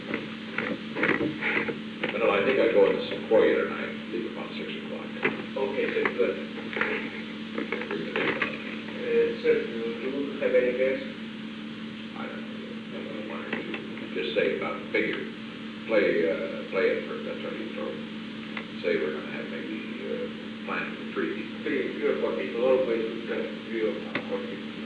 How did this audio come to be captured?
Secret White House Tapes Location: Executive Office Building